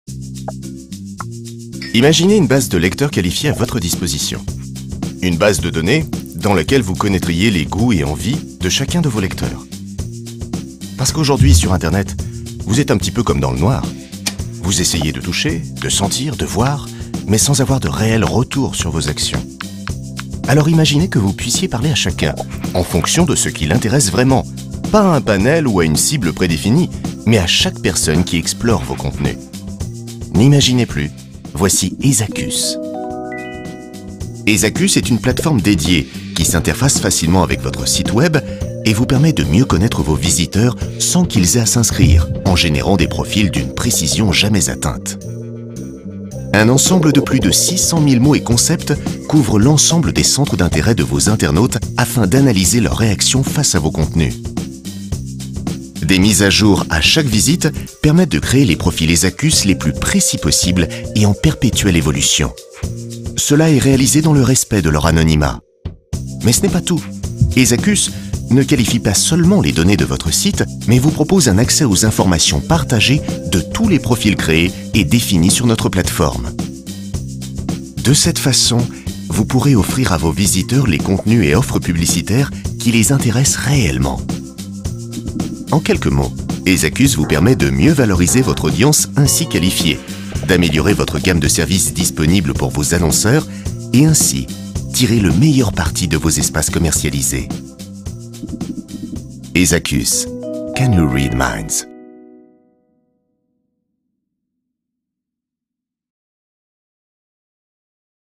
Voix-off Ezakus : naturelle, élégante, rassurante et adaptée à votre histoire
Voix simple et rassurante.
J’ai eu l’occasion de travailler sur le projet Ezakus, une entreprise innovante dans le domaine de la technologie. Pour ce projet, j’ai utilisé une voix grave, apportant une note d’autorité et de sérieux, tout en étant naturelle et rassurante. Un ton élégant et posé pour transmettre la sophistication et la modernité de cette entreprise.